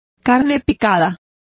Complete with pronunciation of the translation of mince.